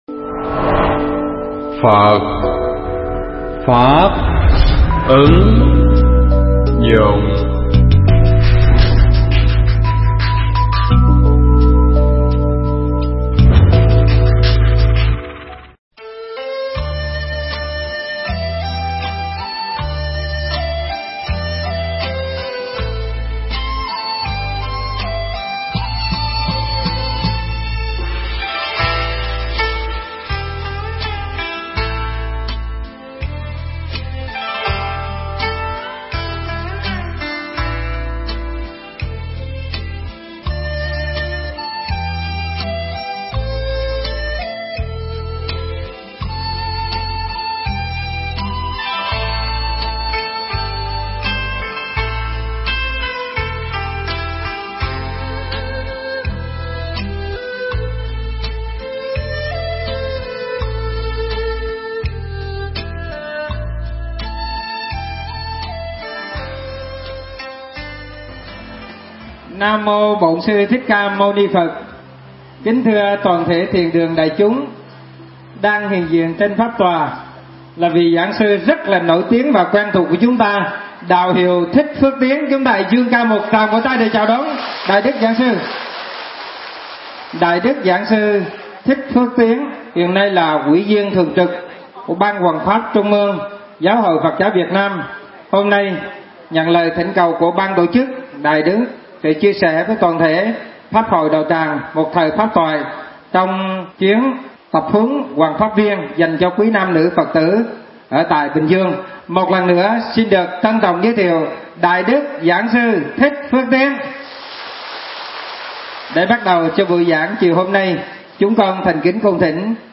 Mp3 Pháp thoại Ngũ Minh - Phương Châm Hoằng Pháp
chùa Hội An - thành phố mới Bình Dương